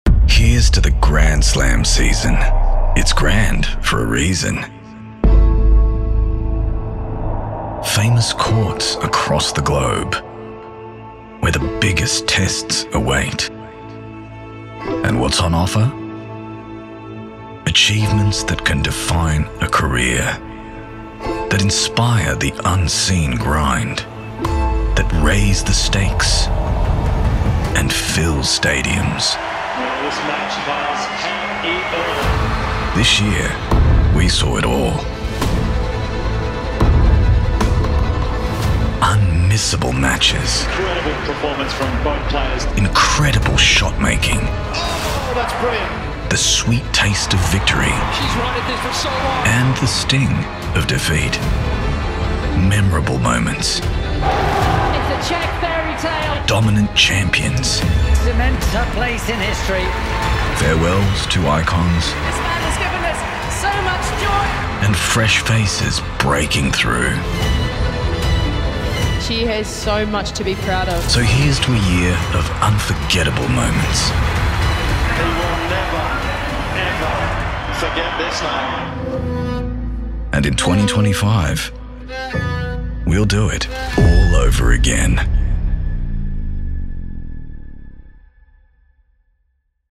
Male
Television Spots
Words that describe my voice are Deep, Tenor, Credible.